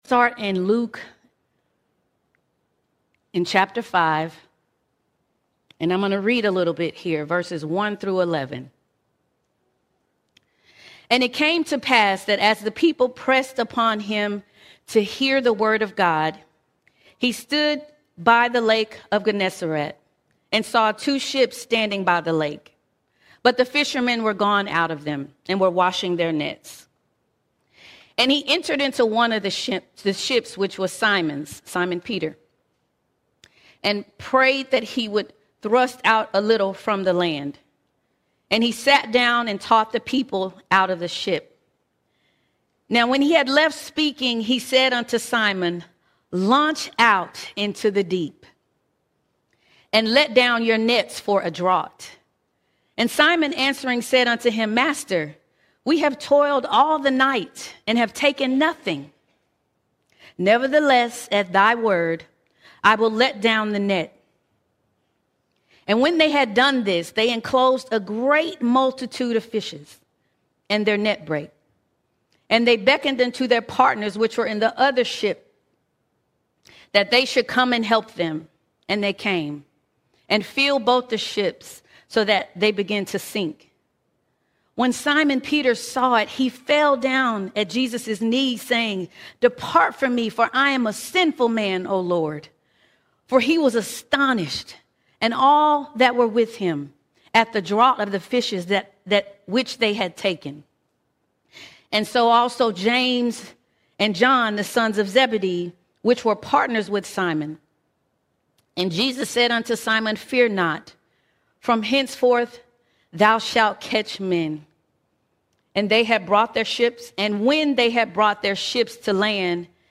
20 October 2025 Series: Sunday Sermons All Sermons Into The Deep Into The Deep No matter how far we have come, there is always more in God.